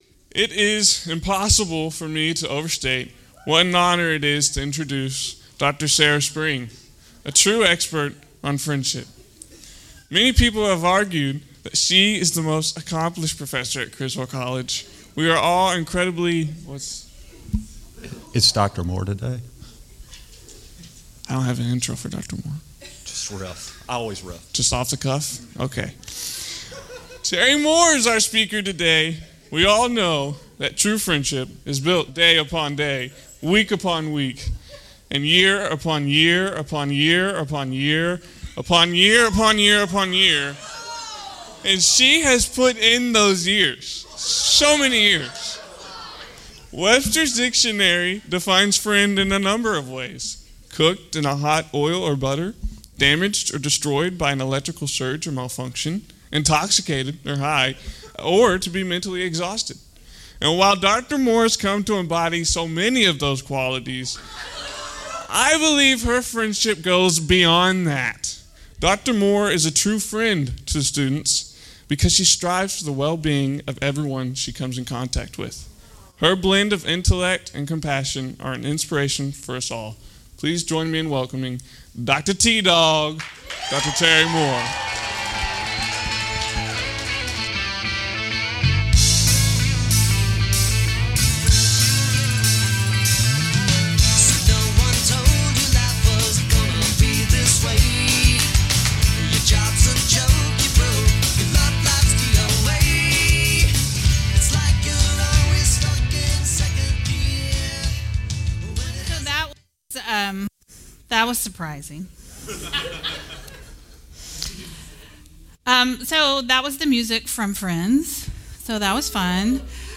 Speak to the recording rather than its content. Criswell College Wednesdays Chapel.